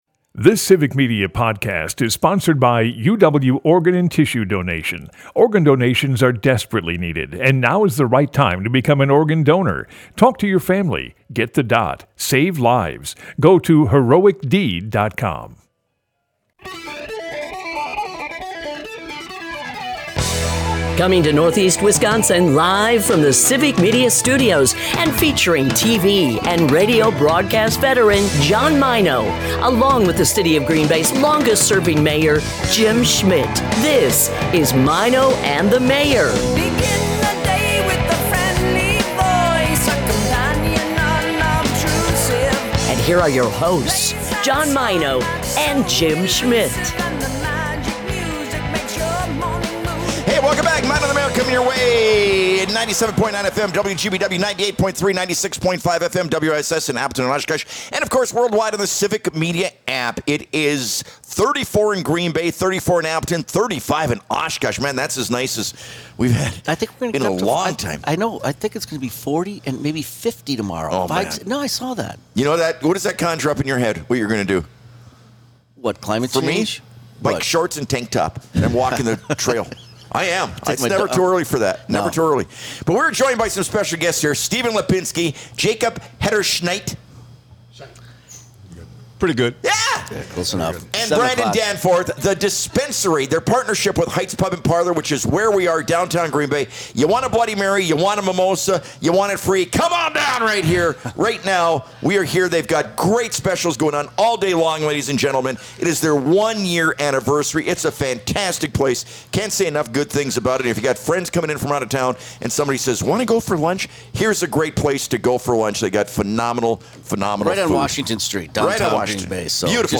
The guys continue their live broadcast from Heights Pub & Parlor for their one-year anniversary! A great group of folks stop by, from listeners to guests!